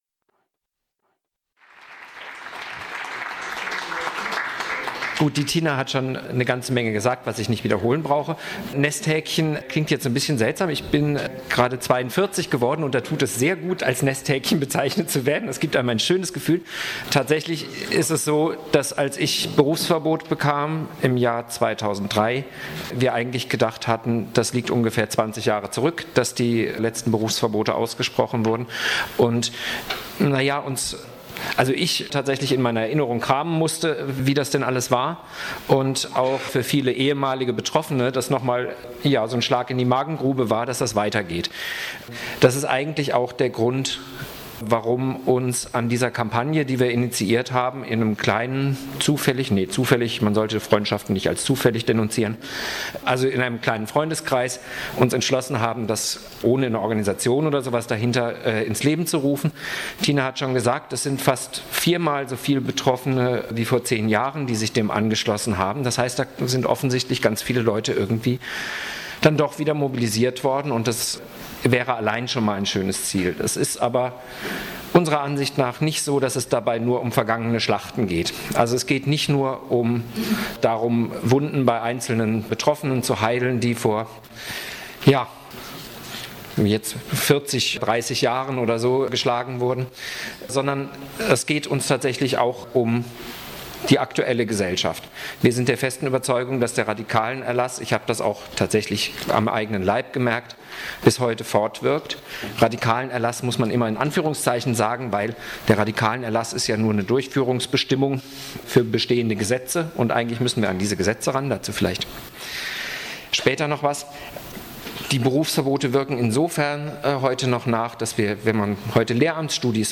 Polithistorische Revue mit 17 Zeitzeuginnen und Zeitzeugen aus 8 Bundesländern,